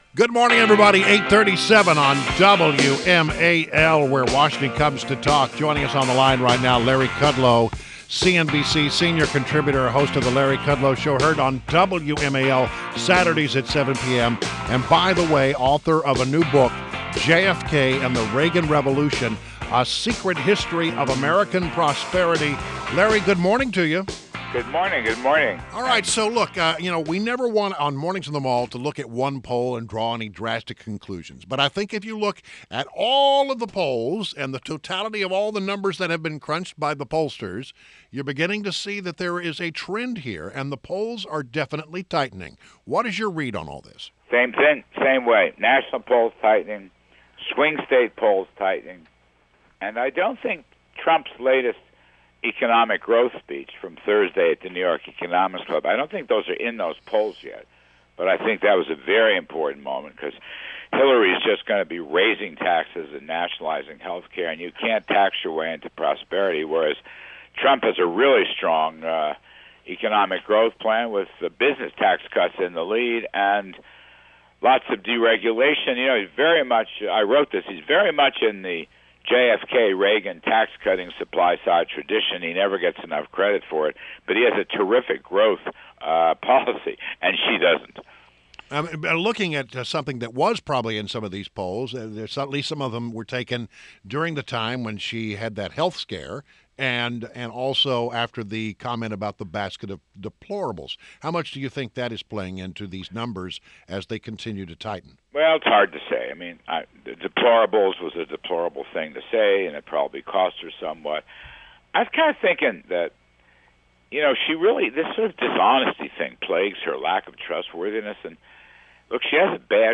INTERVIEW — LARRY KUDLOW – CNBC Senior Contributor and host of The Larry Kudlow Show on WMAL Saturdays at 7 pm – discussed the polls tightening between Clinton and Trump.